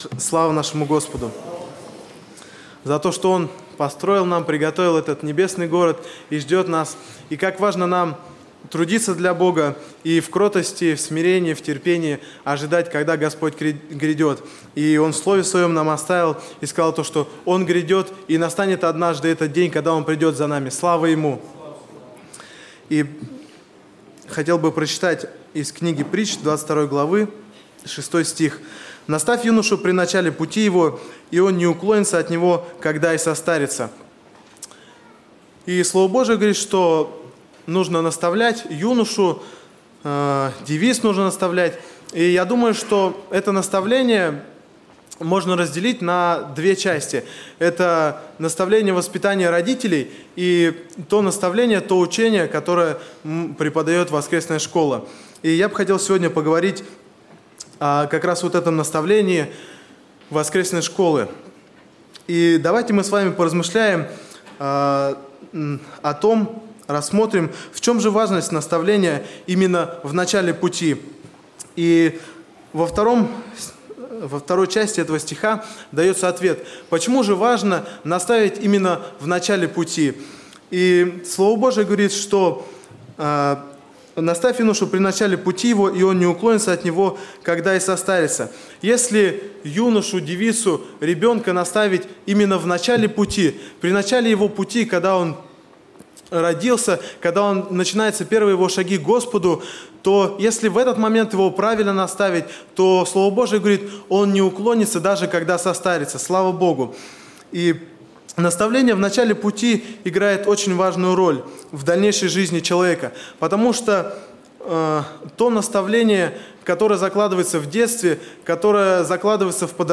Богослужение 26.05.2019
Пение